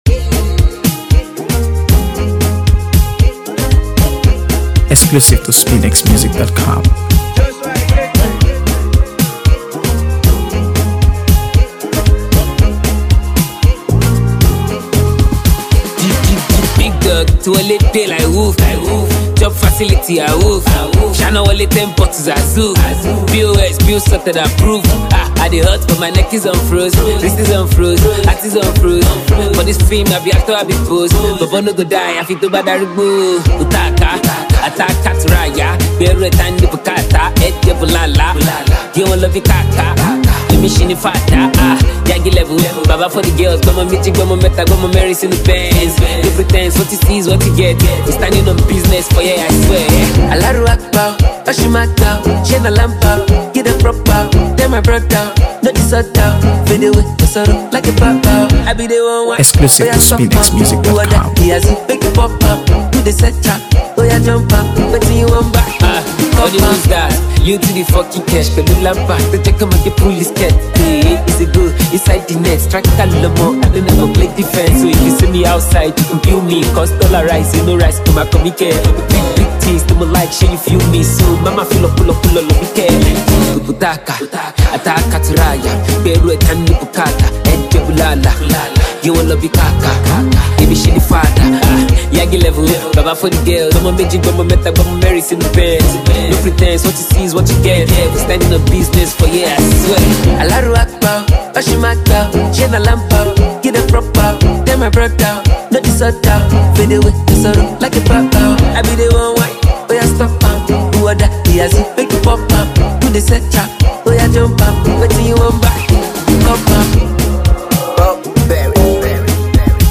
AfroBeats | AfroBeats songs
Known for his energetic delivery and streetwise lyrics
blending catchy production with a confident, feel-good vibe.
sharp, stylish, and undeniably replay-worthy